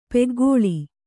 ♪ peggōḷi